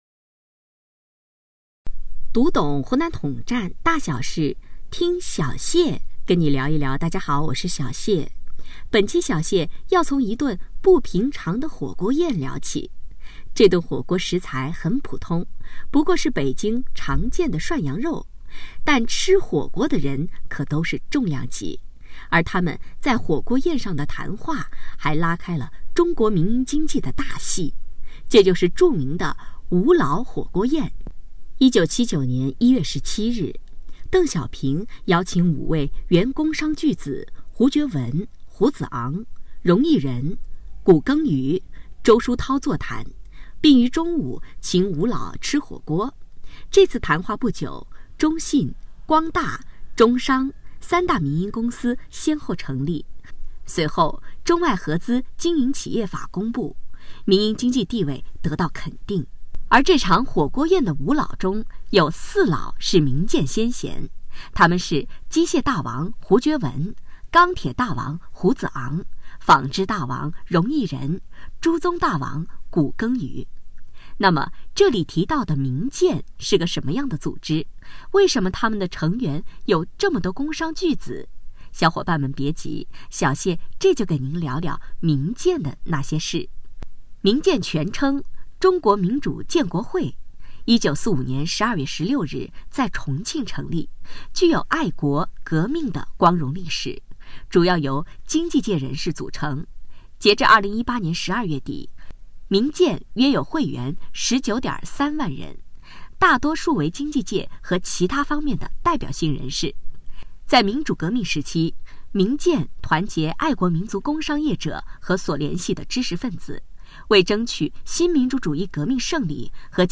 栏目主播采用更生活化、更口语化、更亲切的播音路子，能让统战知识以一种更加有亲和力的方式传播，增强了统战宣传的生动性和感染力，让栏目办出自己的特色与风格，能够在广大统战成员和更广泛的读者群中都产生了较好的影响,从而用创新方式打造一款统战宣传精品。